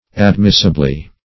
-- Ad*mis"si*ble*ness , n. -- Ad*mis"si*bly , adv.
admissibly.mp3